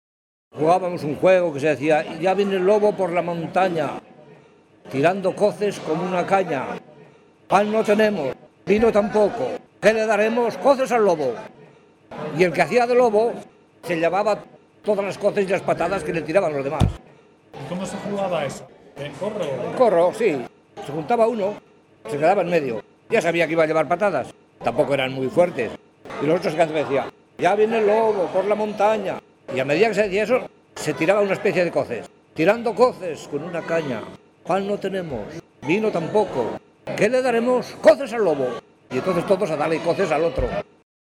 Clasificación: Juegos
Localidad: Valdemoro (Alto Linares, Soria)